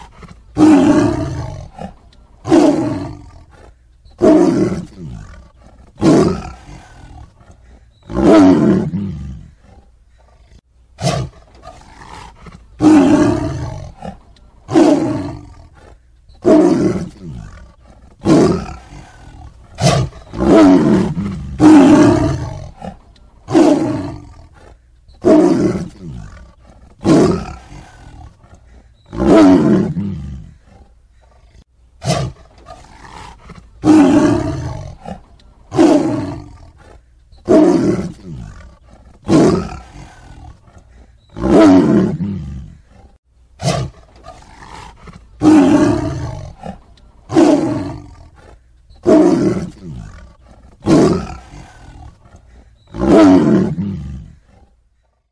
Tiếng Chúa Sơn Lâm gầm rú
Thể loại: Tiếng động vật hoang dã
Và đây là tiếng Chúa Sơn Lâm gầm rú. Âm thanh vang lên trong thiên nhiên rộng lớn của hổ thể hiện sự hung dữ, tức giận, âm thanh đặc trưng của thú hoang dã nơi rừng thiêng nước độc.
Tieng-chua-son-lam-gam-ru-www_tiengdong_com.mp3